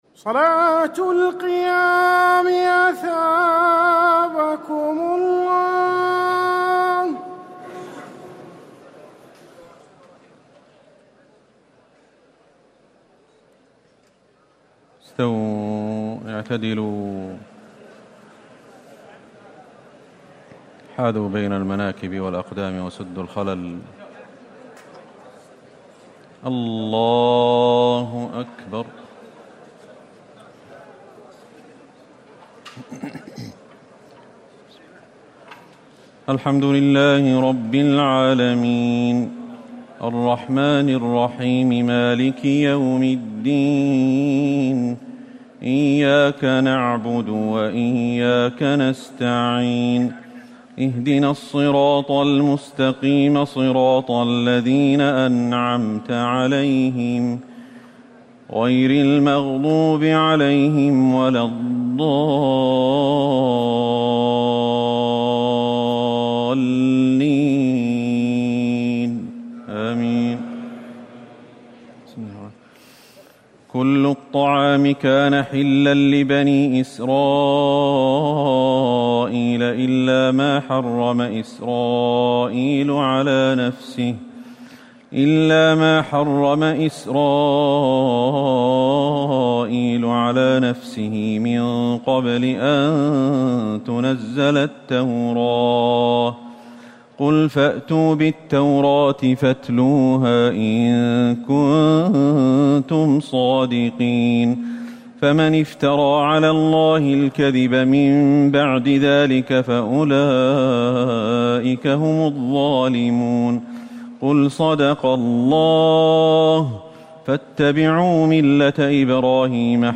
تهجد ليلة 24 رمضان 1439هـ من سورة آل عمران (93-167) Tahajjud 24 st night Ramadan 1439H from Surah Aal-i-Imraan > تراويح الحرم النبوي عام 1439 🕌 > التراويح - تلاوات الحرمين